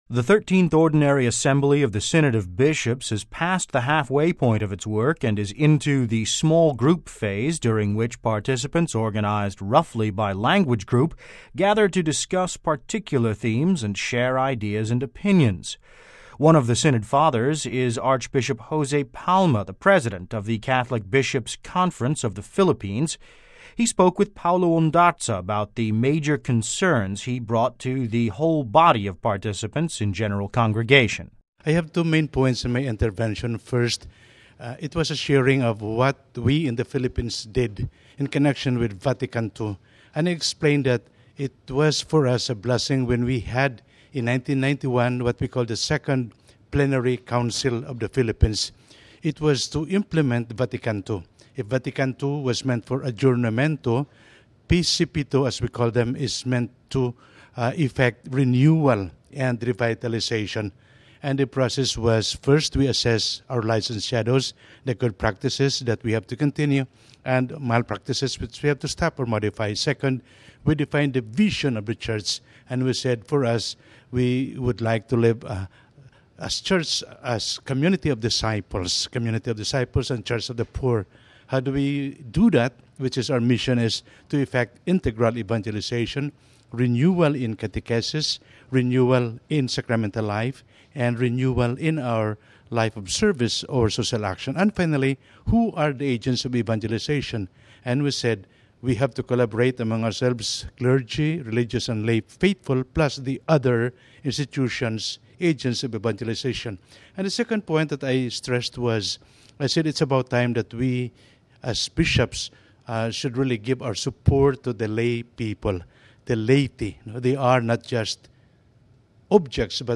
He spoke with Vatican Radio about the major concerns he brought to the whole body of participants in General Congregation, saying, "[I]t was a sharing of what we, in the Philippines, did in connection with Vatican II.”